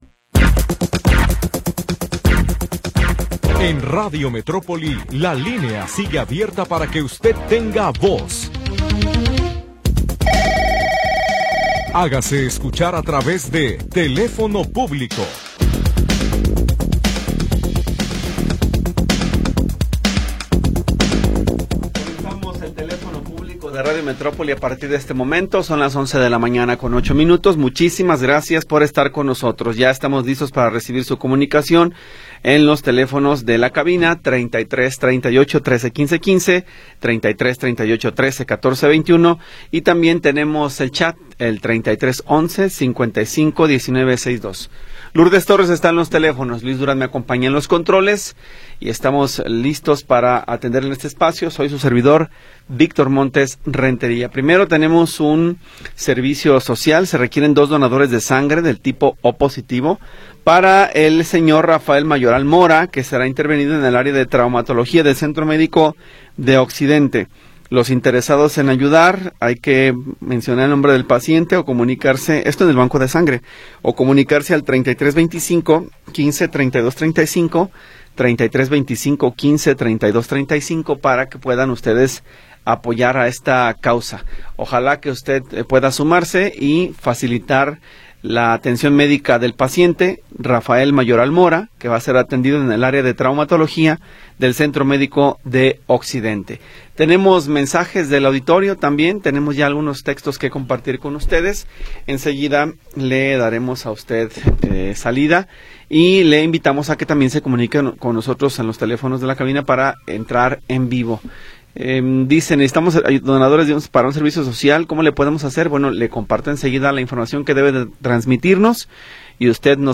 Programa transmitido el 18 de Agosto de 2025.